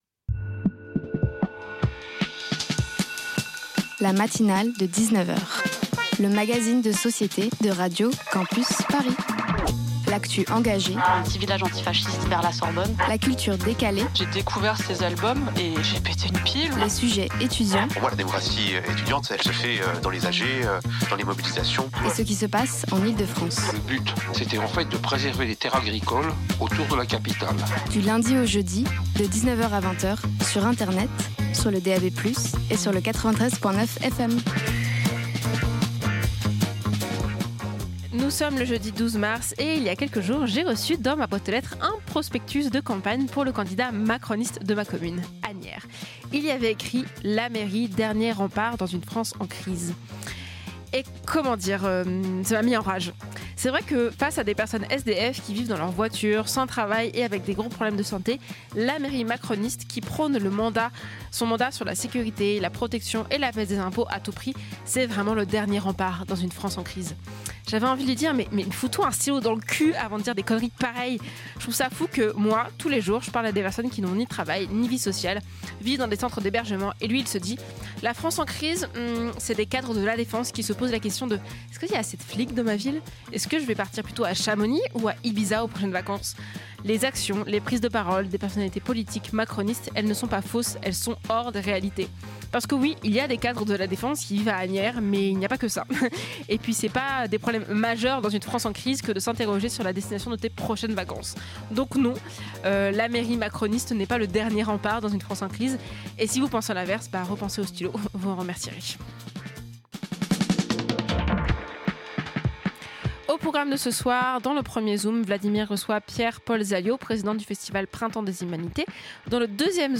Radio Campus Paris est la radio associative et locale des étudiants et des jeunes franciliens.